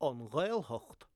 An Ghaeltacht An Ghaeltacht An Ghaeltacht Note that in Cork Irish, the letter "t" is almost always pronounced as a "h" when it follows the letter "l".
anghaelthacht.wav